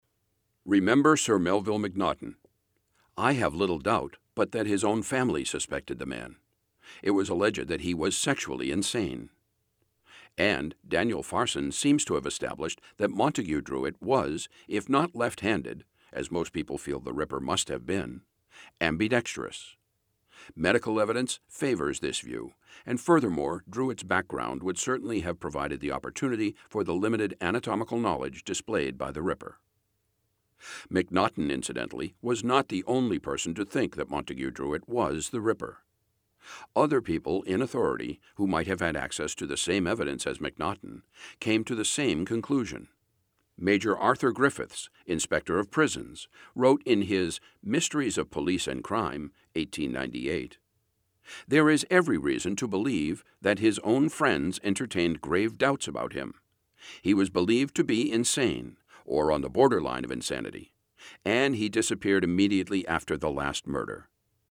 Audio Books